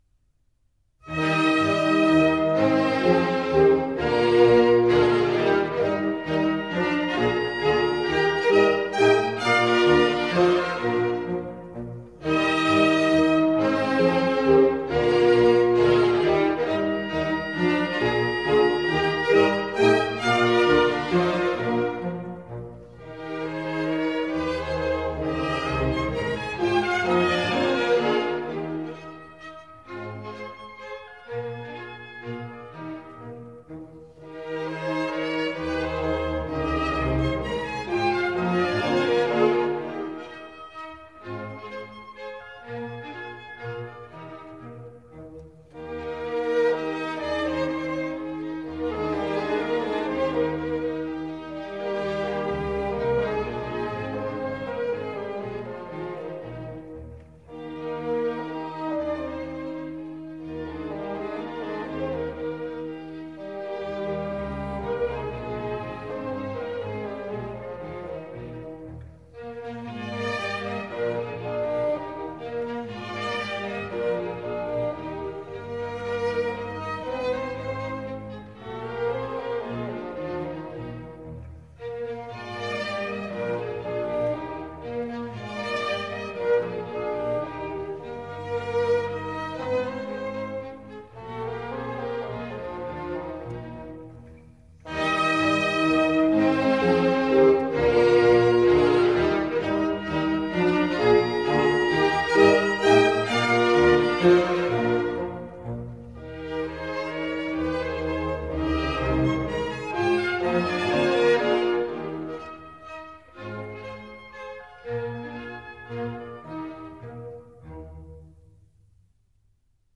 E Flat Major